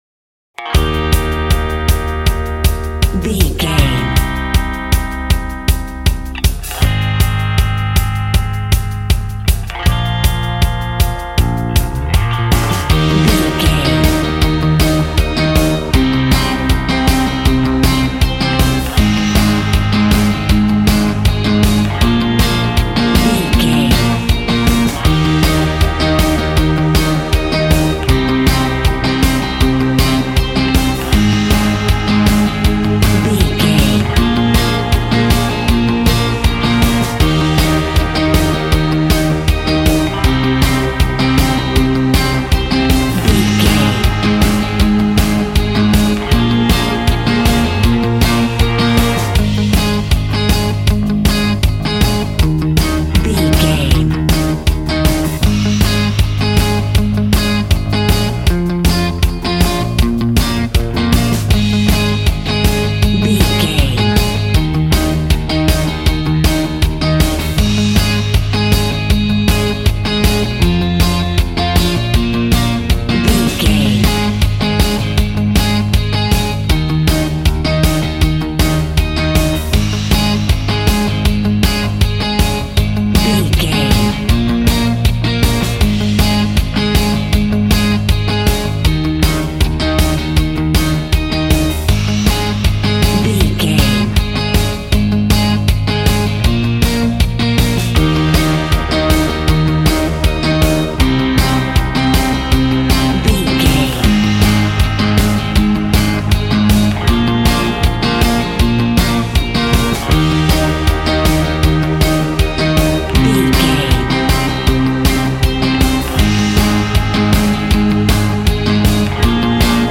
Ionian/Major
E♭
groovy
powerful
organ
drums
bass guitar
electric guitar
piano